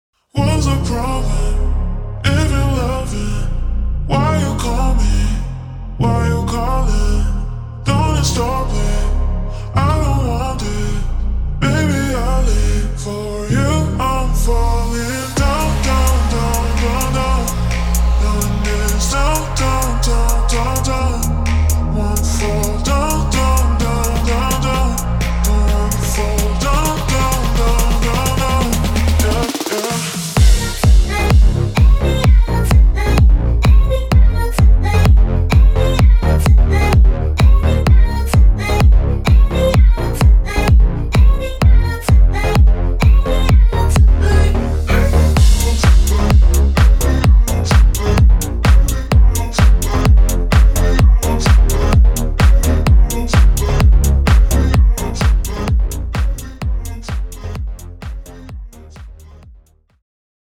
EDM
• 25 Drum Loops